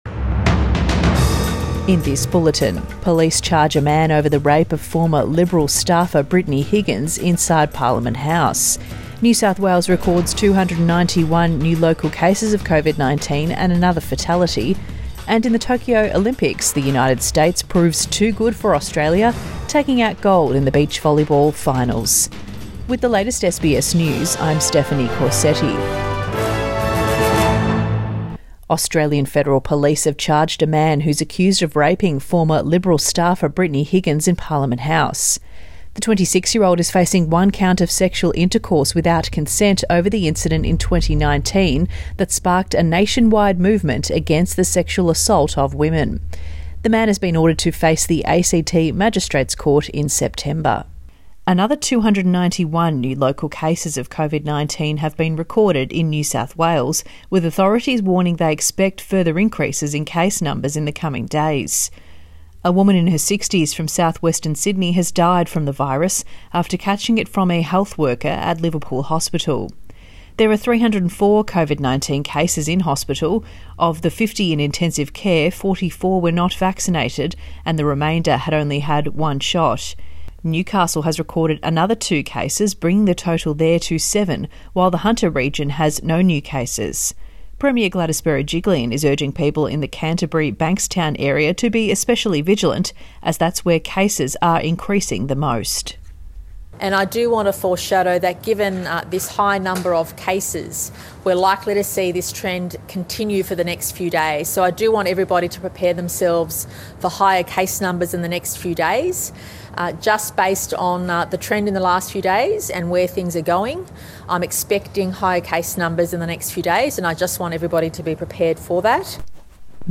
PM bulletin 6 August 2021